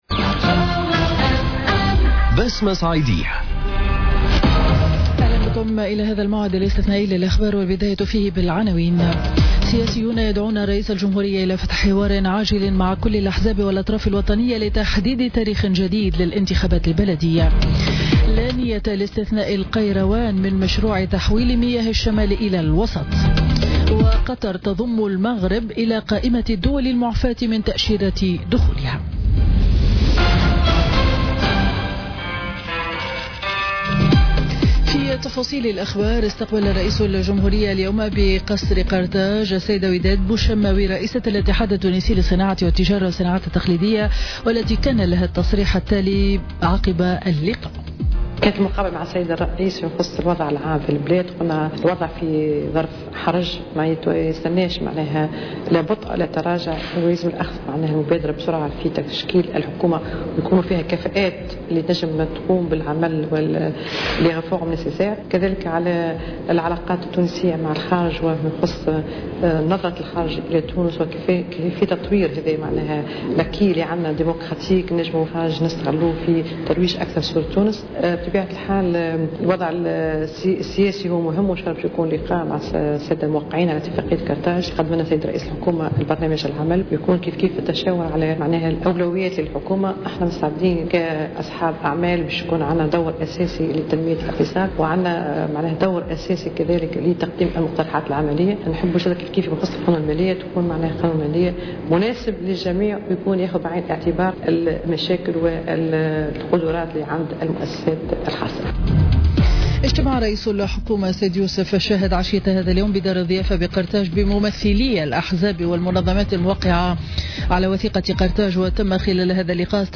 نشرة أخبار السابعة مساء ليوم الثلاثاء 5 سبتمبر 2017